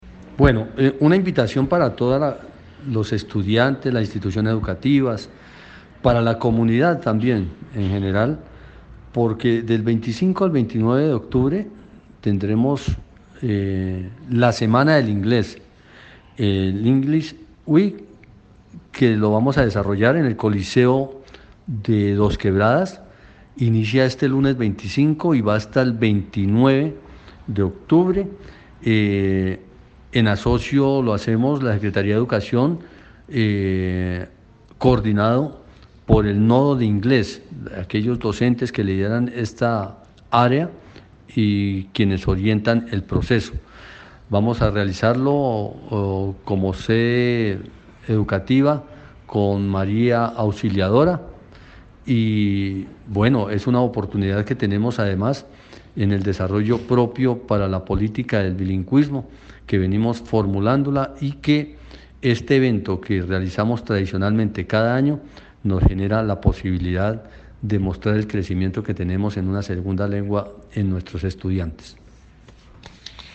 Comunicado-712-Audio-Secretario-de-Educacion-Celso-Omar-Parra.mp3